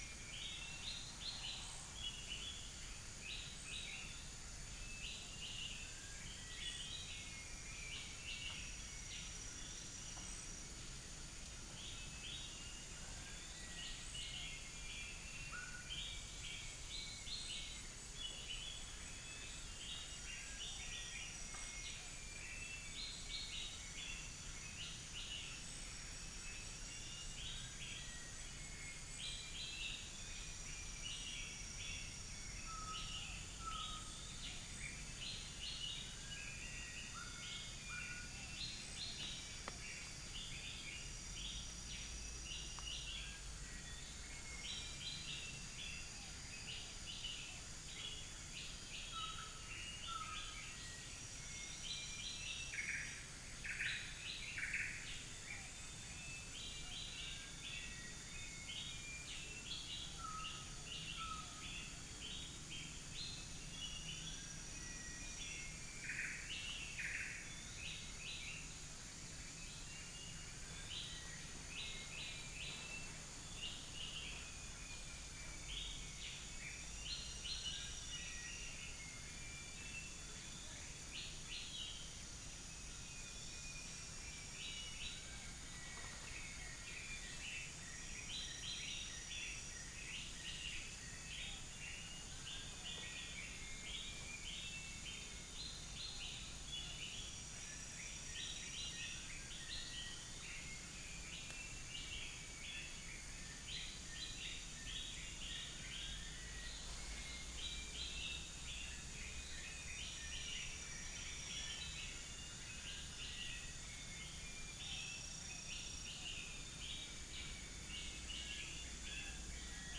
Upland plots dry season 2013
Platysmurus leucopterus
Dicrurus paradiseus
Malacopteron affine